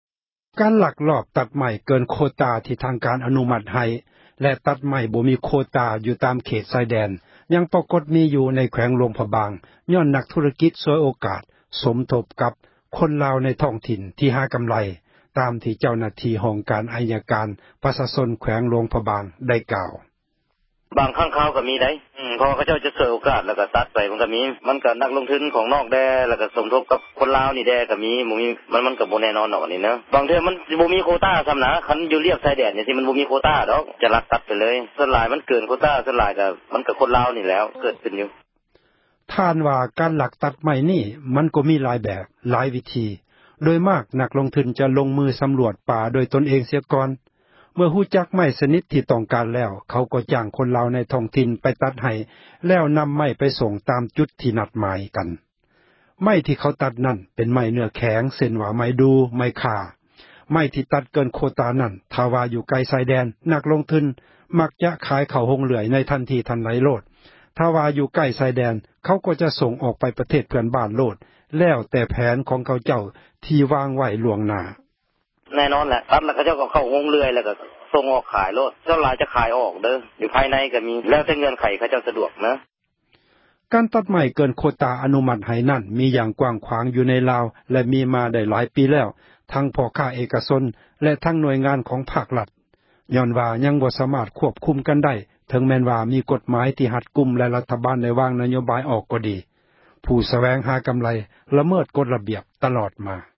ການລັກຕັດໄມ້ເກີນ ໂຄຕາ ທີ່ທາງການ ອະນຸມັດໃຫ້ ແລະ ຕັດໄມ້ບໍ່ມີໂຄຕາ ຢູ່ຕາມເຂດ ຊາຍແດນ ຍັງປາກົດມີ ຢູ່ໃນແຂວງ ຫລວງພຣະບາງ ຍ້ອນນັກ ທຸຣະກິດ ສວຍໂອກາດ ສົມທົບ ກັບຄົນລາວ ໃນທ້ອງຖິ່ນ ທີ່ຫາກໍາໄຣ, ຕາມທີ່ເຈົ້າໜ້າທີ່ ຫ້ອງການ ໄອຍະການ ປະຊາຊົນ ແຂວງ ຫລວງພຣະບາງ ໄດ້ກ່າວ: